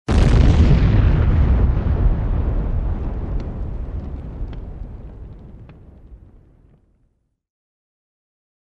explosion.mp3